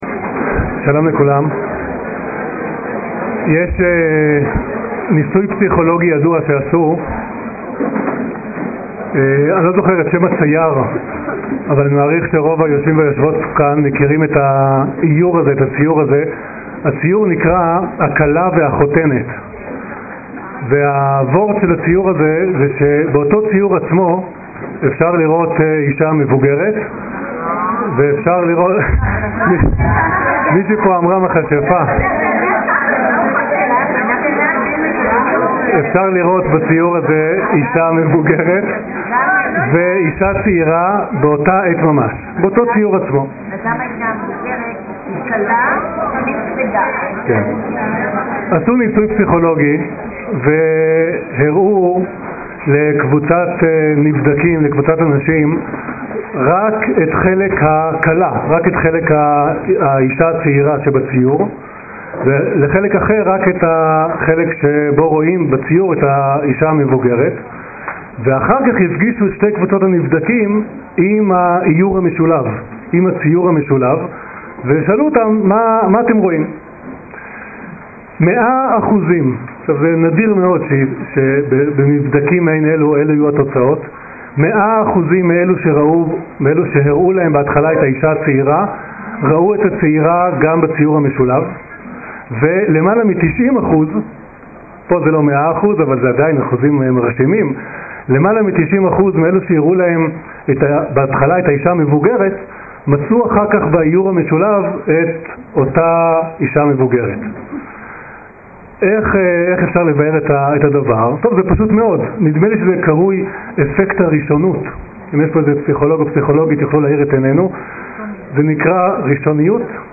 השיעור באדיבות אתר התנ"ך וניתן במסגרת ימי העיון בתנ"ך של המכללה האקדמית הרצוג תשס"ז